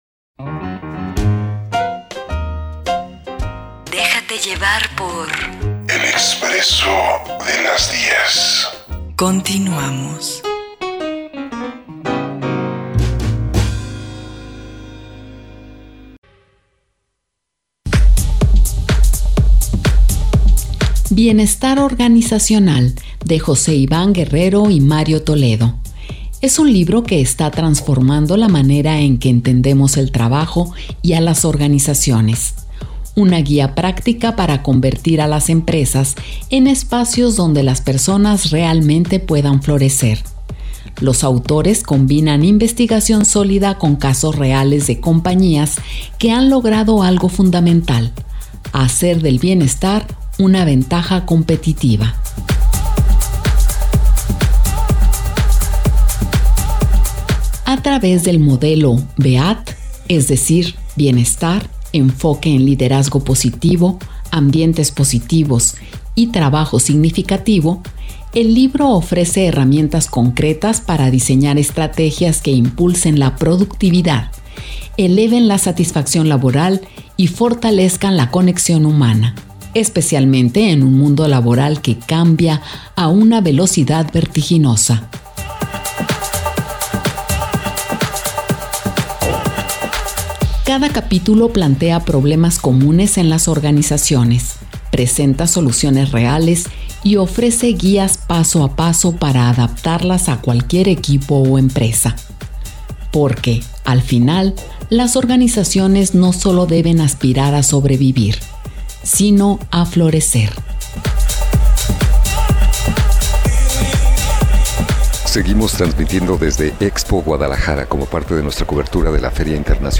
en vivo en El Expresso de las 10 desde Expo Guadalajara